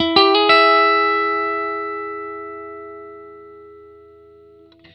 RIFF1-120E+.wav